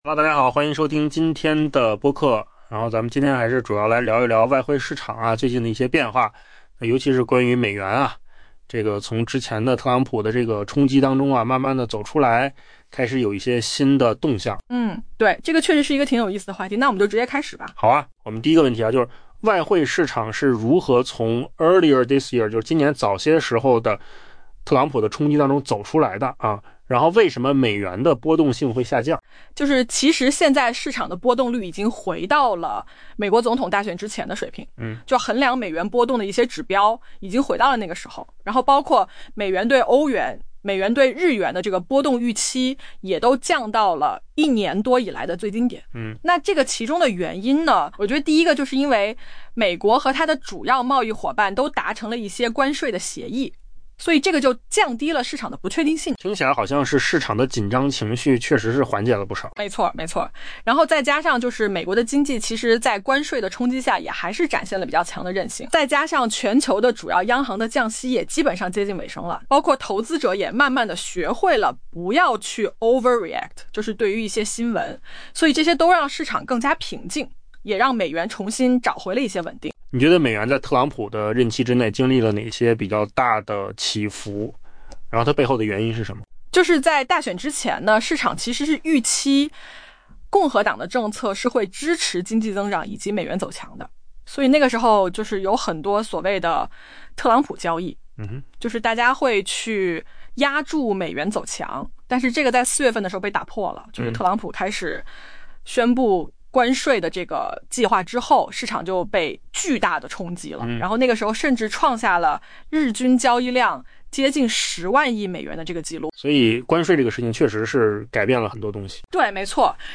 AI 播客：换个方式听新闻 下载 mp3 音频由扣子空间生成 外汇市场似乎已经走出了今年早些时候引发剧烈波动的 「特朗普冲击波」，衡量美元波动性的指标已跌至美国总统大选前的水平。